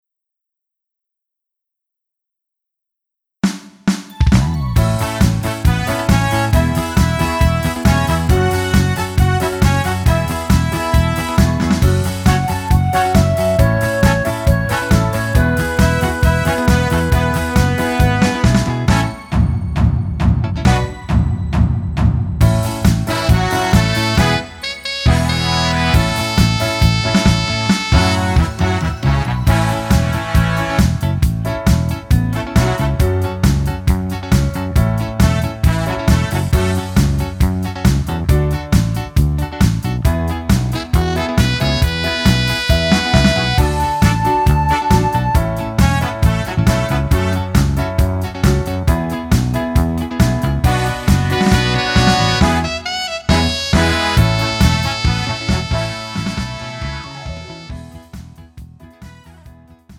음정 -1키 3:30
장르 가요 구분 Pro MR